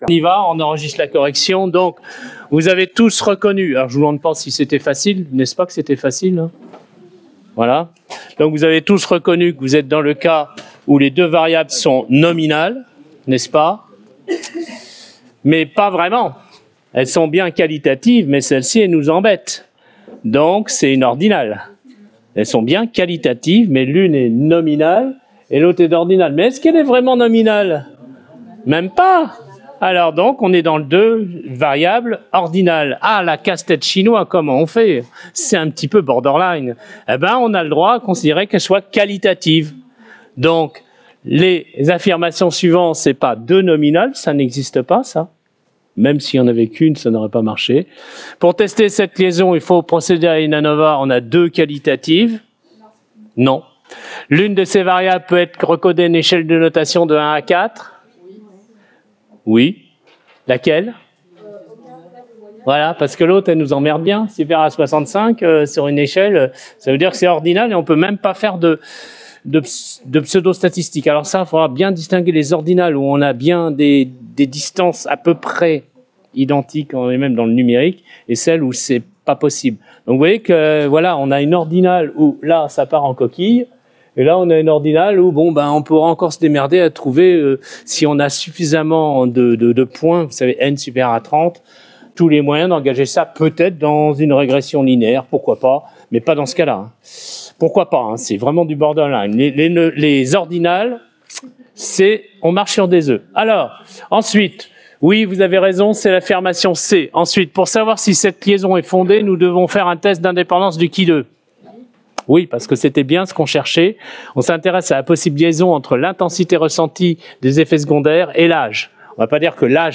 Correction succinte du Jeu N° 4 (fichier audio non monté, brut de décoffrage)